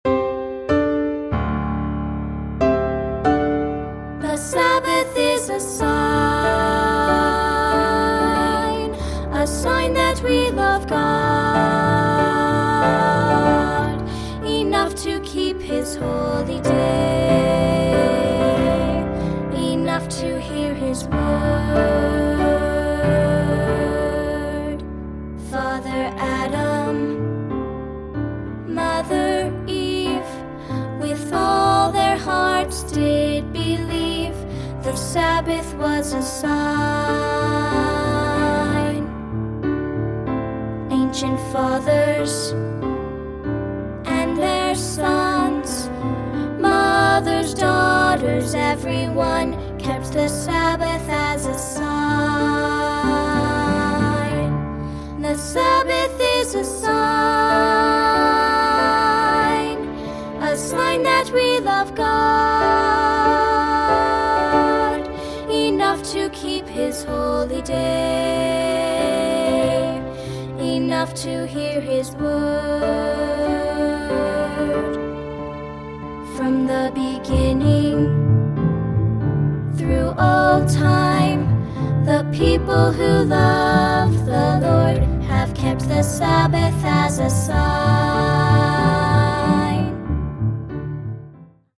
for solo vocal with three-part women’s choir.